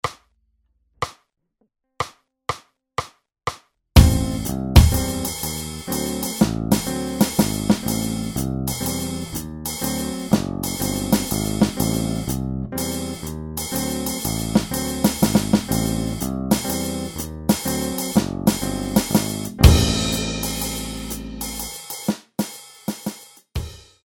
Ma ora è arrivato il momento di complicare il tutto variando la ritmica della mano Dx.